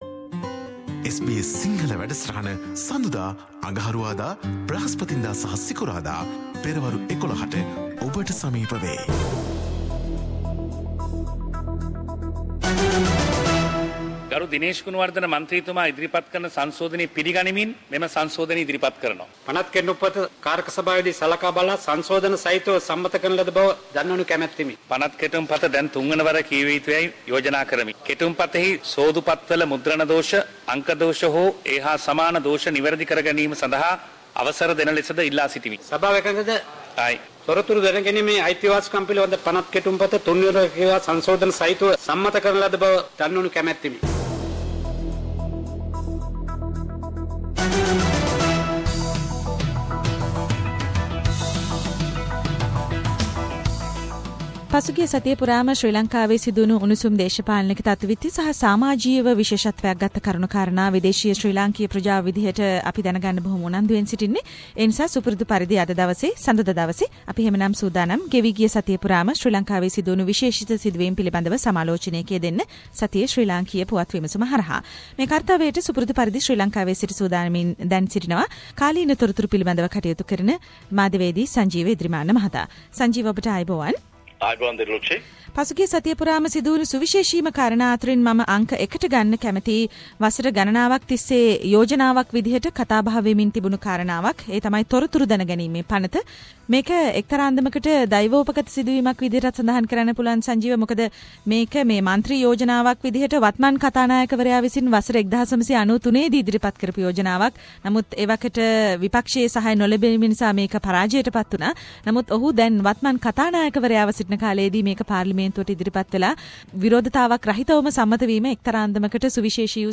news wrap